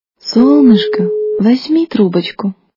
» Звуки » Люди фразы » Солнышко мое! - Возьми трубочку
При прослушивании Солнышко мое! - Возьми трубочку качество понижено и присутствуют гудки.